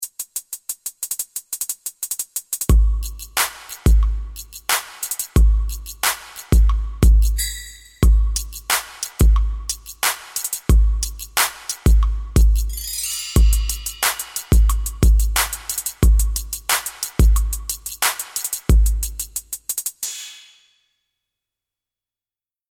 Rap - RnB
Hip-hop